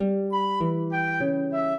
flute-harp
minuet12-6.wav